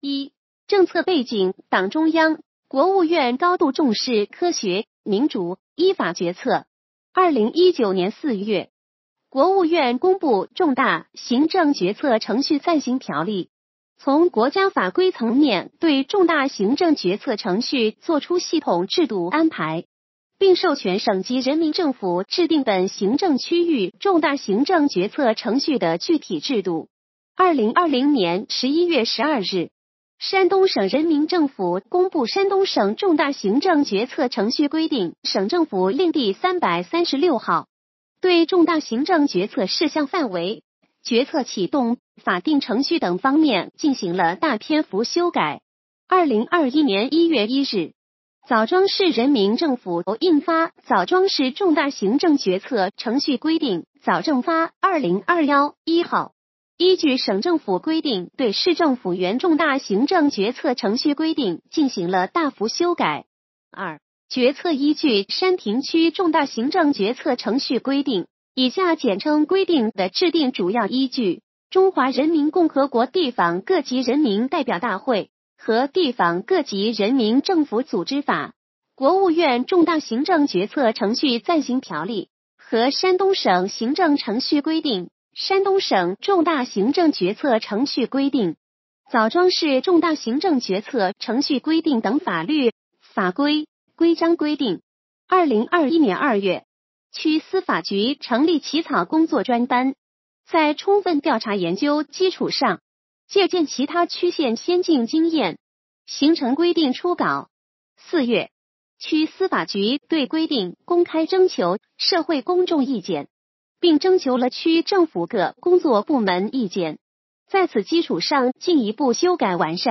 语音解读：山亭区人民政府关于公布山亭区人民政府2021年度重大行政决策事项目录的通知